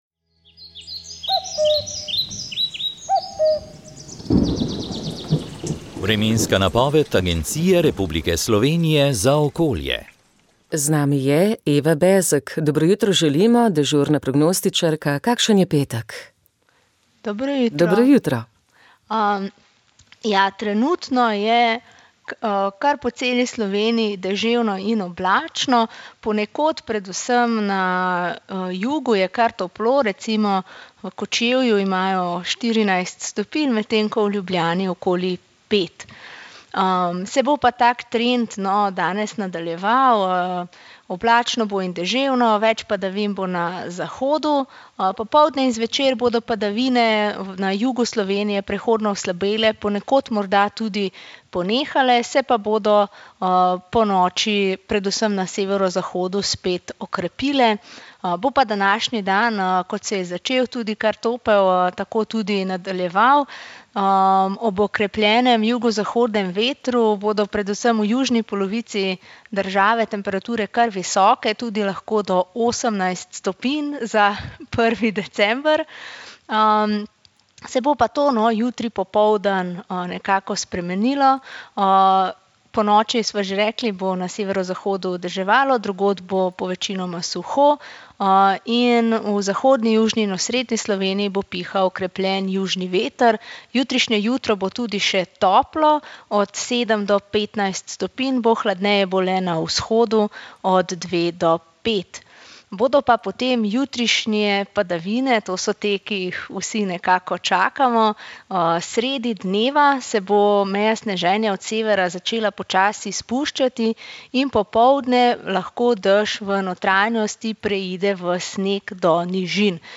Vremenska napoved 21. maj 2023